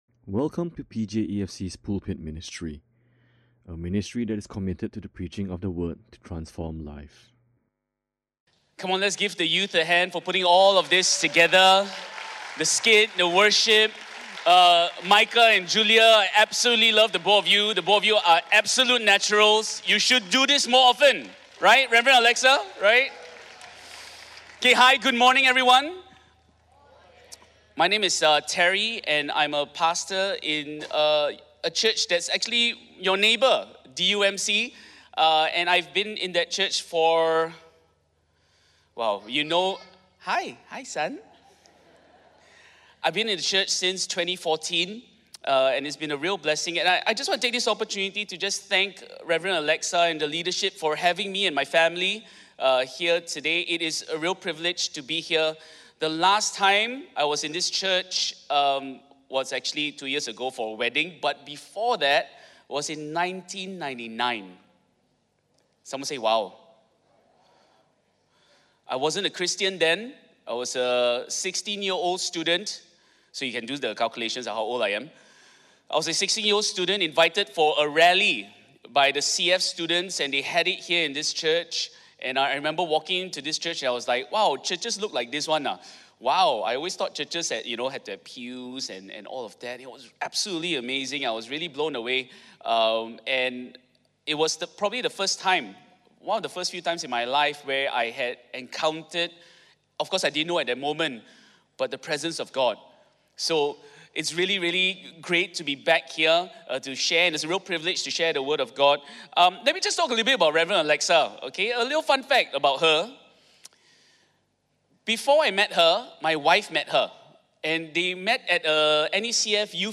This is a stand-alone sermon.